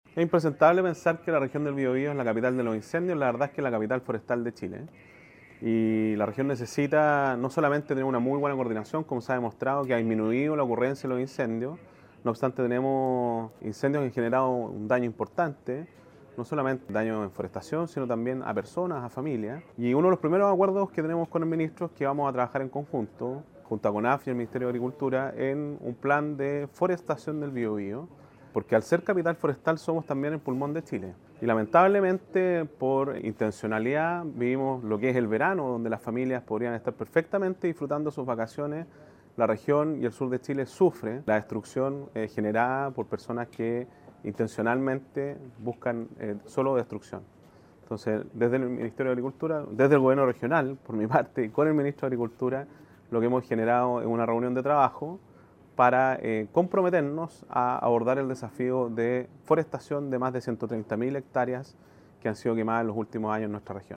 Además de evaluar el trabajo, la máxima autoridad regional afirmó que se llegó a dos grandes acuerdos ligados a la recuperación forestal y riego.